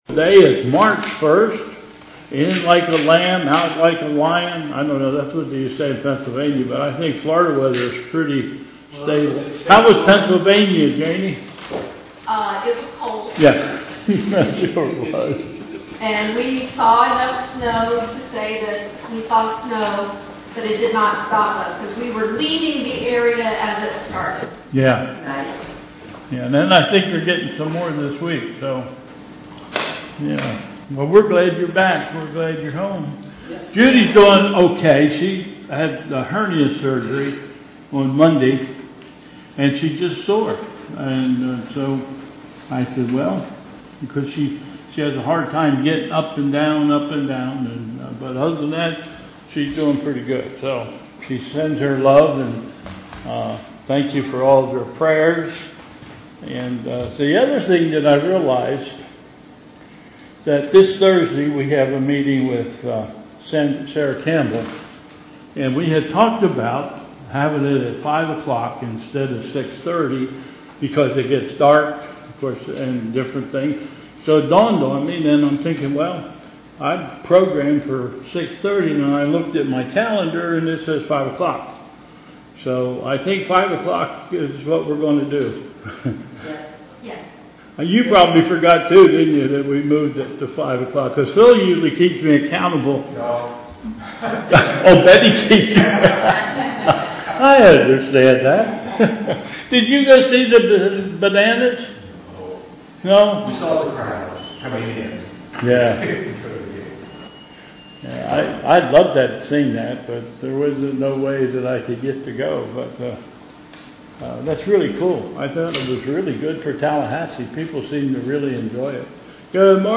Bethel Church Service